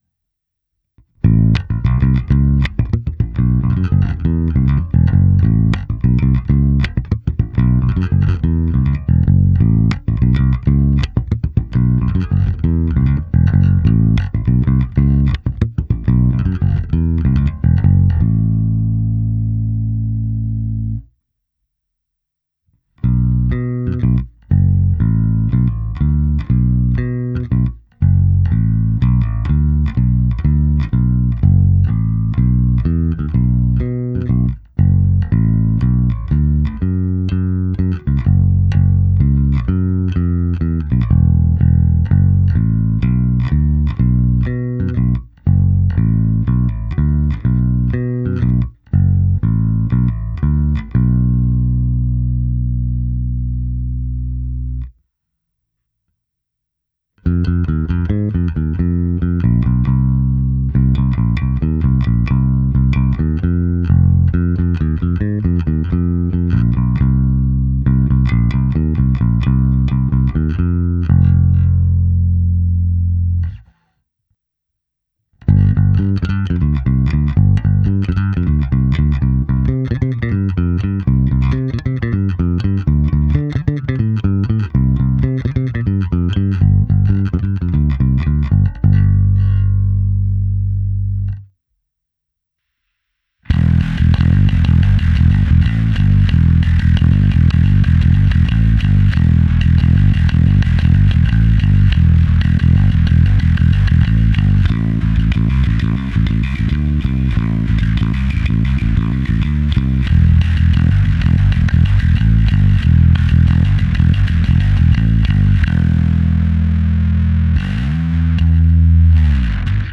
Následující ukázka je na oba snímače přes multiefekt/preamp Zoom MS-60B se zapnutou simulací aparátu, v ukázce je použito i zkreslení a slap.
Ukázka se simulací aparátu